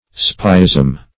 Spyism \Spy"ism\ (-[i^]z'm), n.